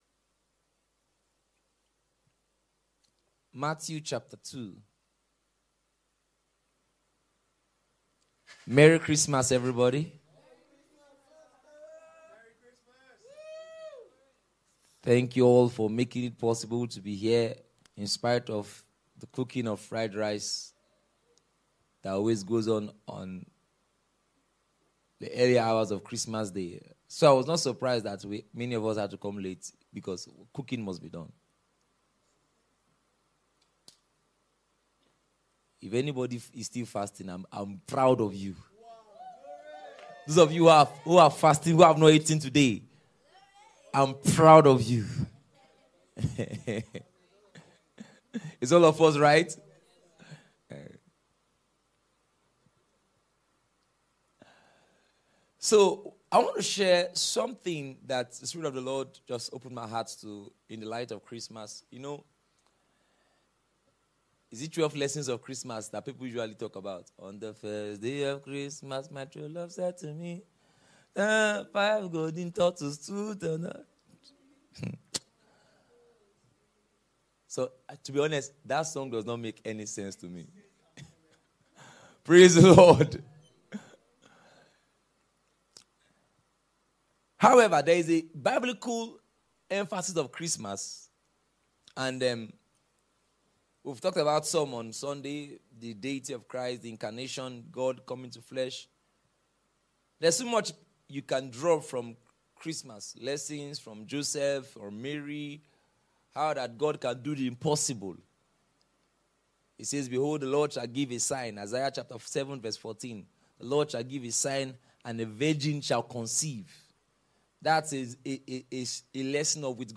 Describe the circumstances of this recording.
Special Christmas Service.mp3